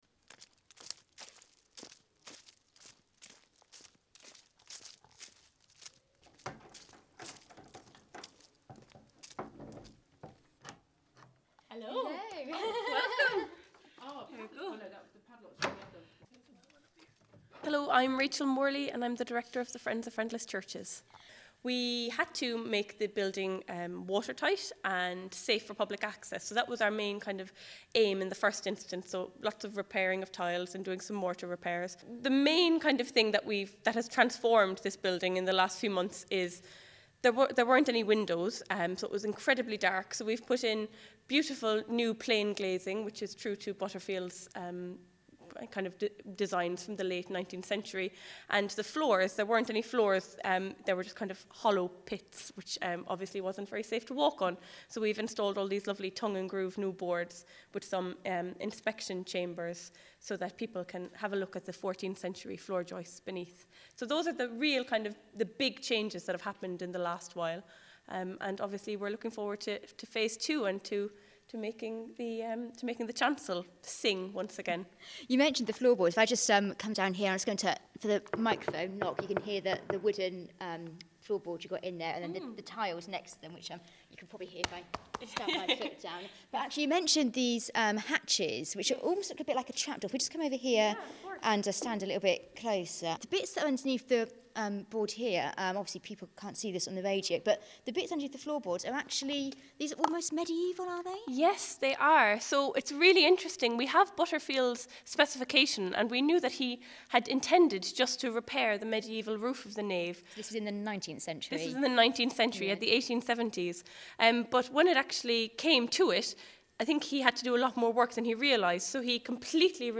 This is an ‘MP3’ sound file – the interviews start about 18 seconds in.
St-Denis-church-East-Hatley-Cambridgeshire-BBC-Radio-Cambridgeshire-interviews-22-7-18.mp3